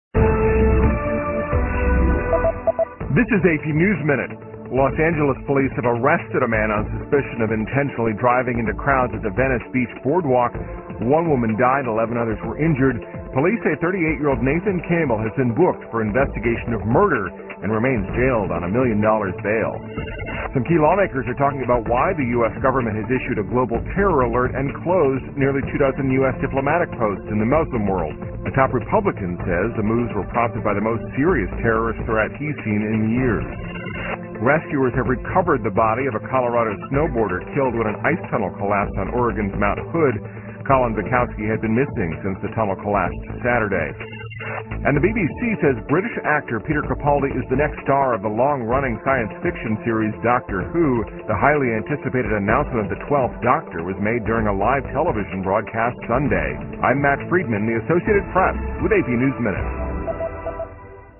在线英语听力室美联社新闻一分钟 AP 2013-08-07的听力文件下载,美联社新闻一分钟2013,英语听力,英语新闻,英语MP3 由美联社编辑的一分钟国际电视新闻，报道每天发生的重大国际事件。电视新闻片长一分钟，一般包括五个小段，简明扼要，语言规范，便于大家快速了解世界大事。